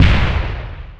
grenade.wav